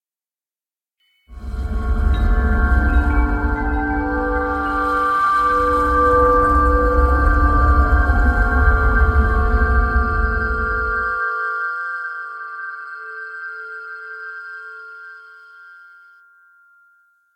Tinnitus_04.ogg